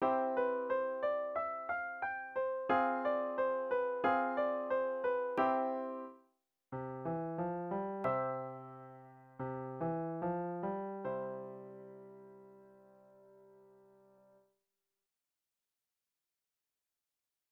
「dim e poco riten.」書かれているところを見ていきましょう。
ですので、「だんだん」なしで書かれているところから「ゆっくり」します。
①の方が音楽の流れに合っているように思います。